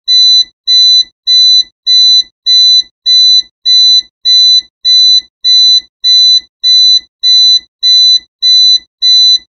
Smoke Alarm Sound Effect Free Download
Smoke Alarm